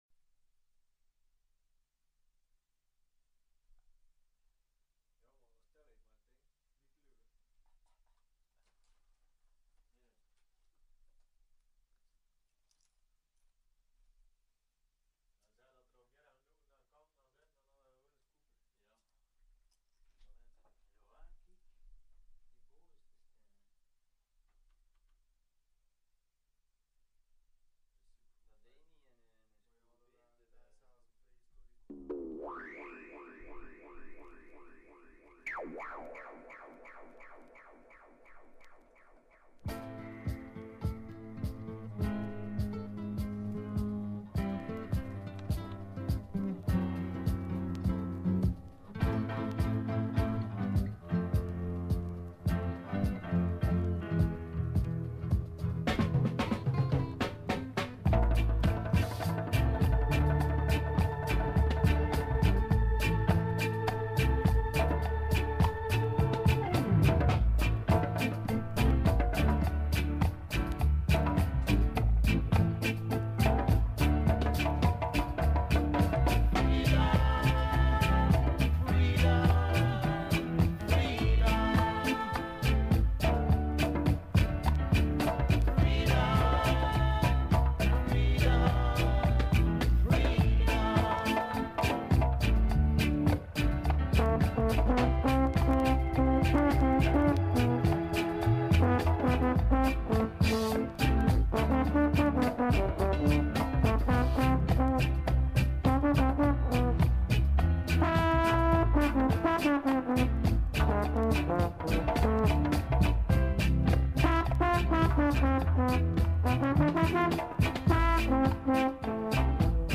live & direct